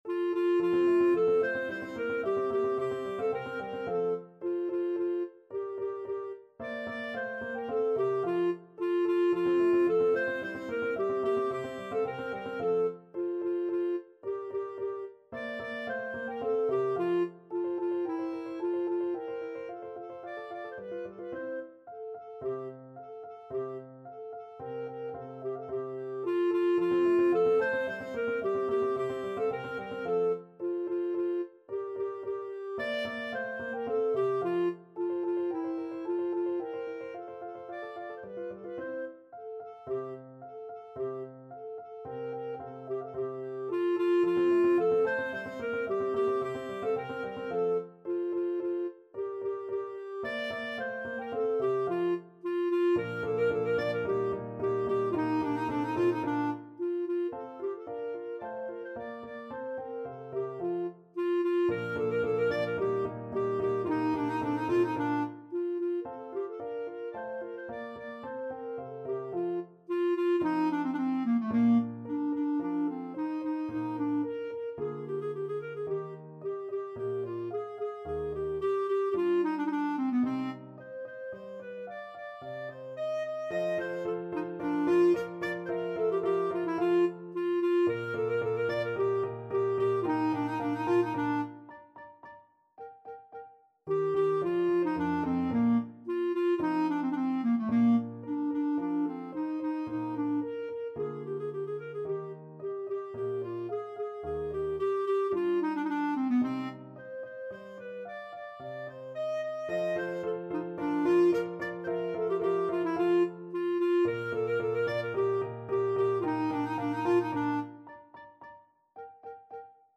Clarinet
F major (Sounding Pitch) G major (Clarinet in Bb) (View more F major Music for Clarinet )
2/4 (View more 2/4 Music)
Vivace assai =110 (View more music marked Vivace)
A4-Eb6
Classical (View more Classical Clarinet Music)